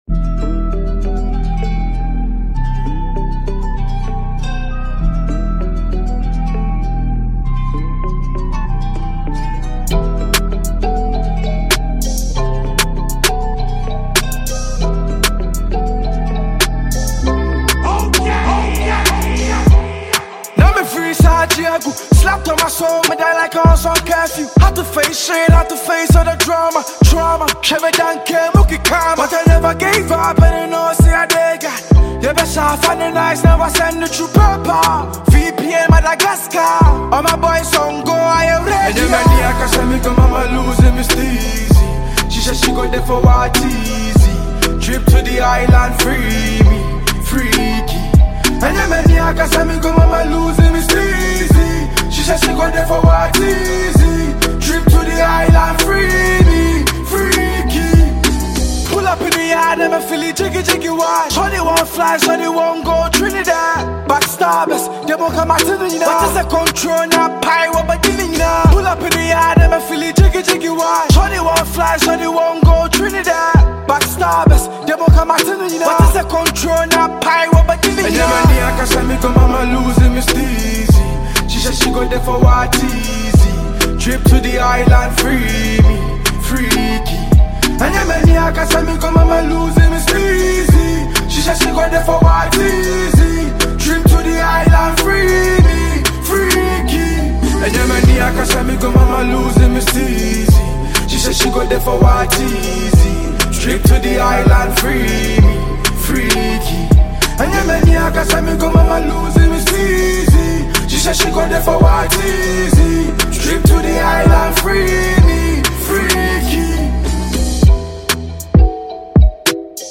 Ghanaian young rapper